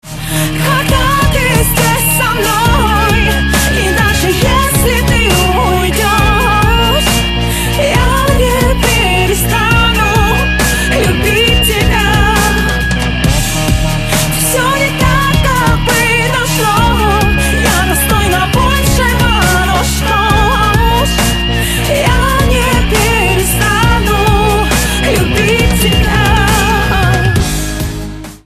• Качество: 128, Stereo
поп
громкие